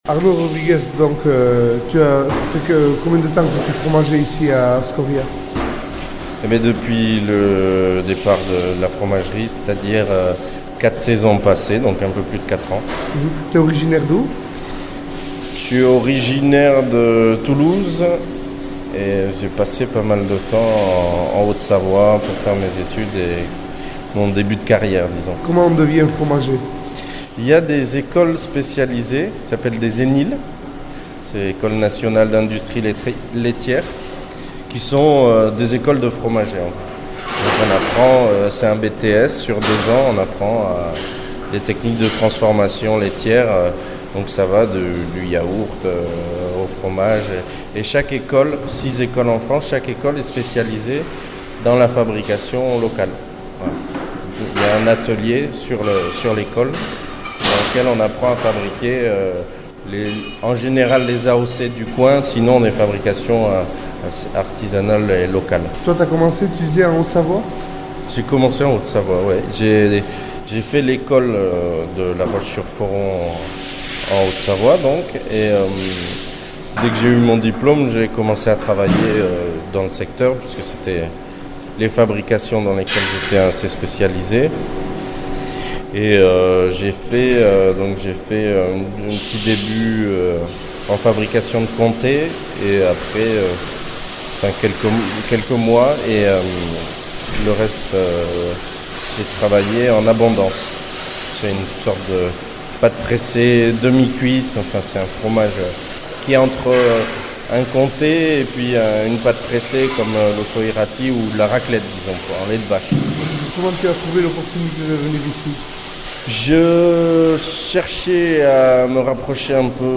Entzün erreportajea :